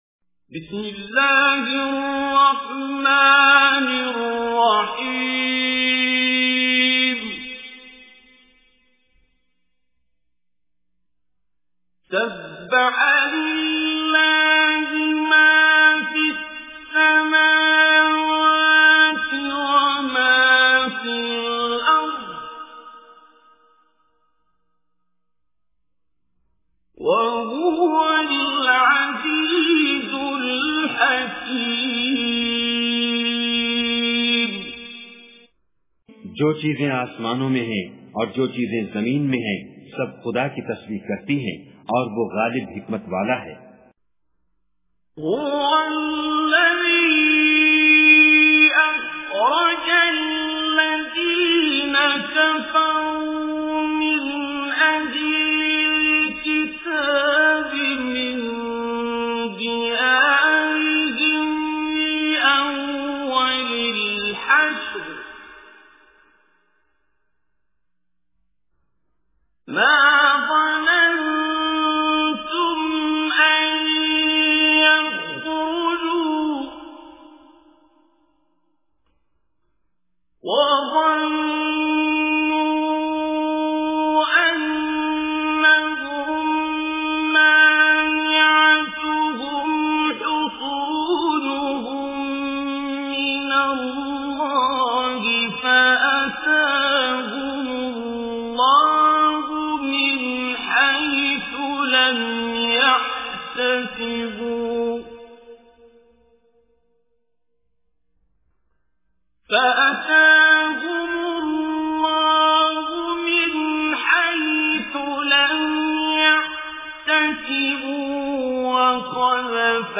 Surah Hashr Recitation with Urdu Translation
Surah Al-Hashr is 59 Surah of Holy Quran. Listen online and download mp3 tilawat / recitation of Surah Al-Hashr in the beautiful voice of Qari Abdul Basit As Samad.